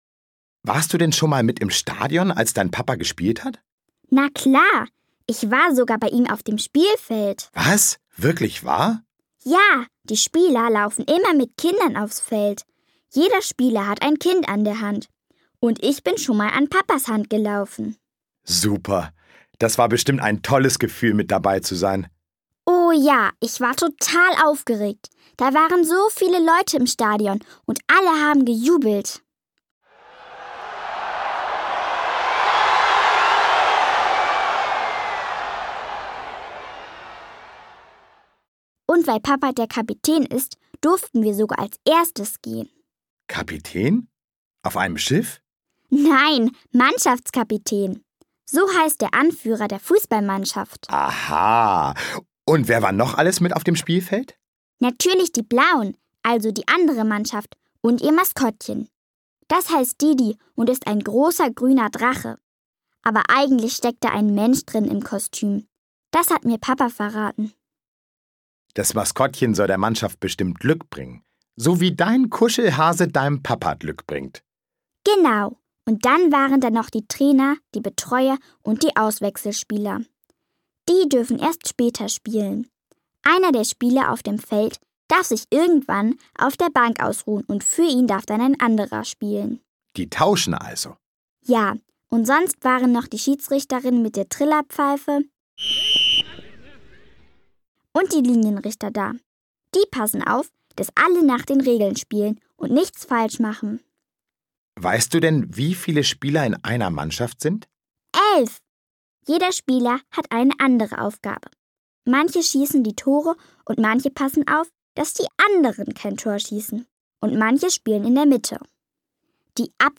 Schlagworte Fußball • Fußballplatz • Fußballspieler • Fußballtraining • Kinderhörbuch • Kindersachbuch • Profifußball • Profisport • Sachhörbuch • spielend lernen • Sport • Wieso?